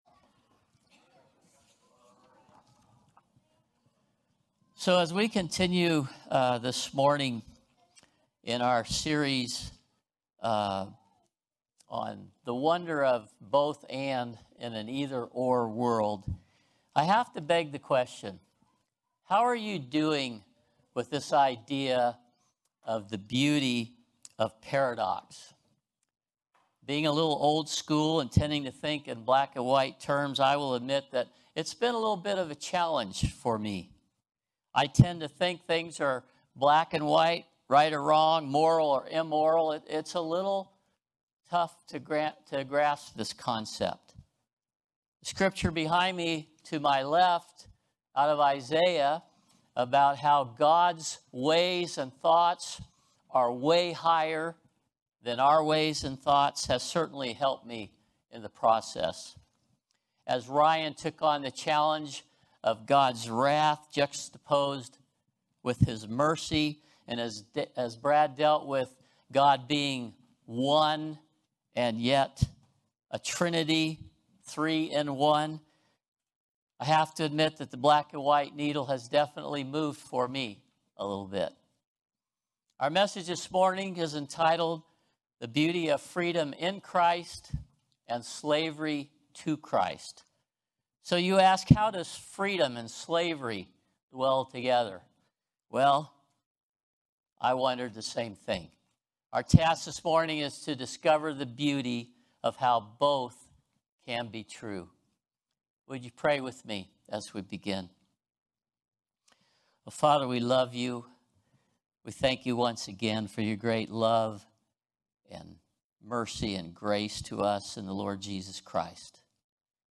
Series: The Beauty of Paradox Type: Sermons